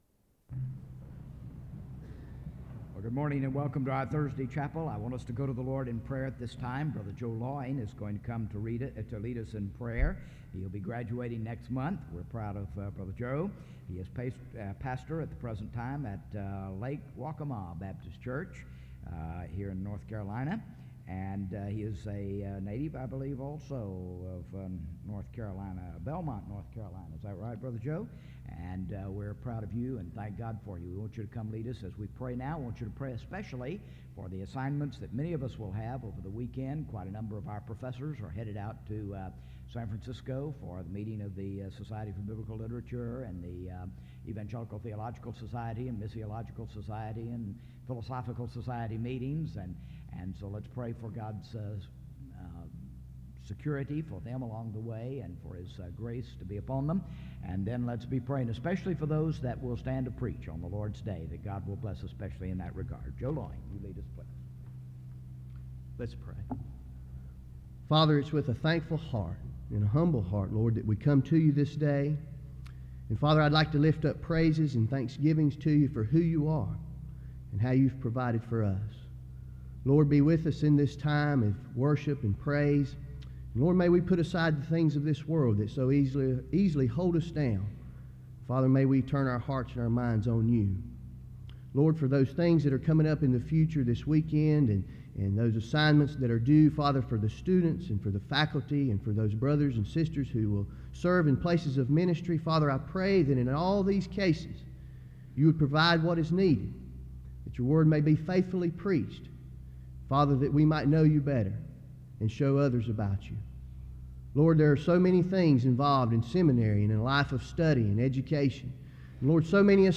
SEBTS Chapel - Zig Ziglar November 19, 1992
SEBTS Chapel and Special Event Recordings